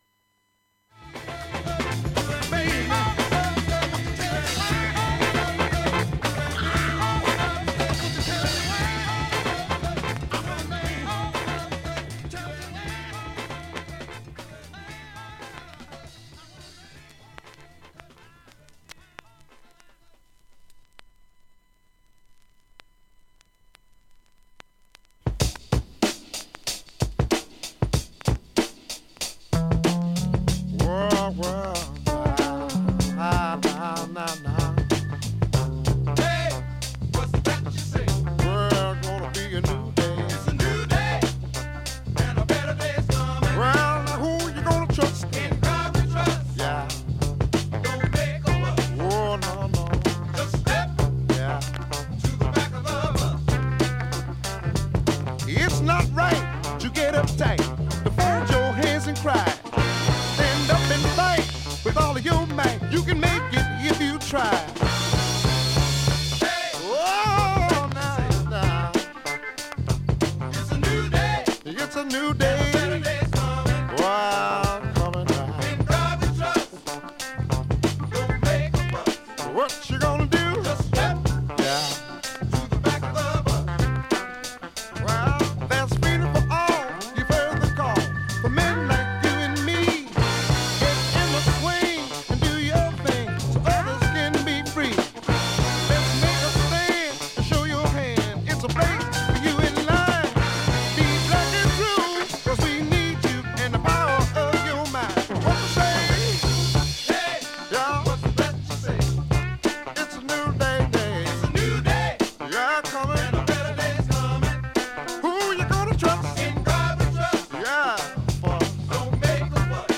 1本薄いスレでプツ出ますが、わずかです。
終わりのフェイド部に数回わずかプツ出ます。
ドラム・ブレイクの定番中の定番、
アルティメット・ブレイクなファンク・チューン